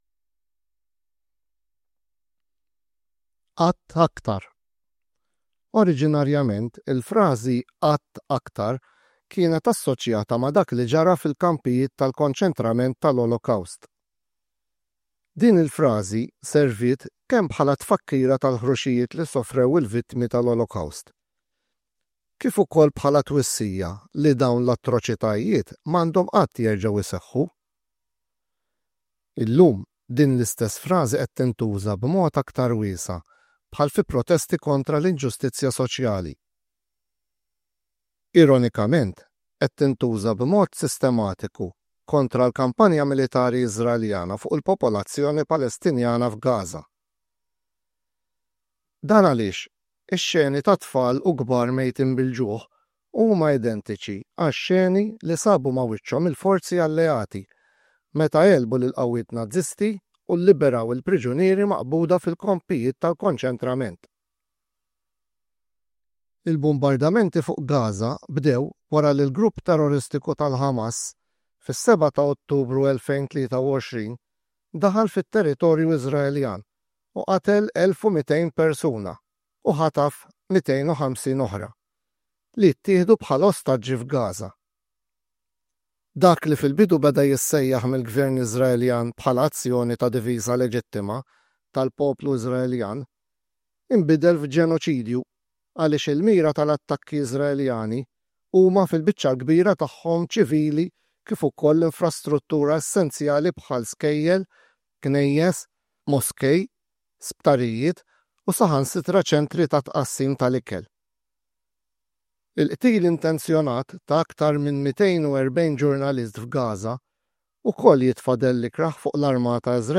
Kull taħriġ jinkludi s-silta moqrija, il-karta tat-taħriġ għall-istudenti u l-karta tal-għalliema bir-risposti.